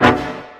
dre horn 2.wav